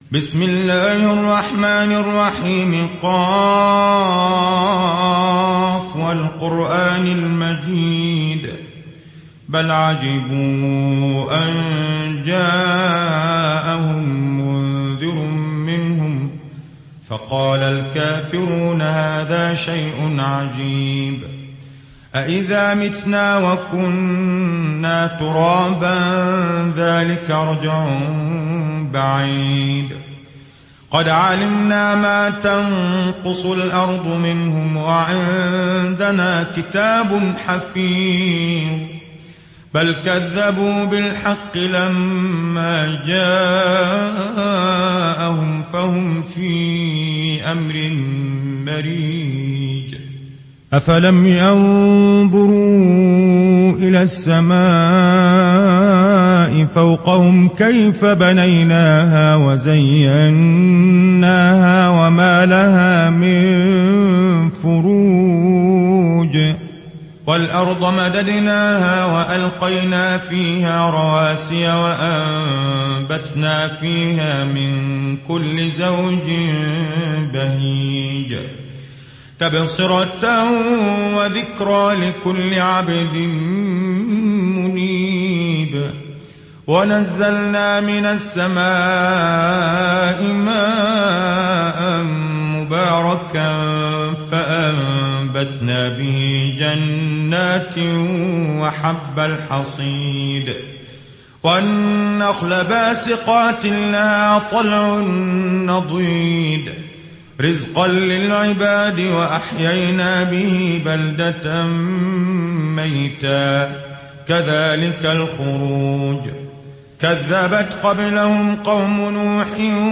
Riwayat Hafs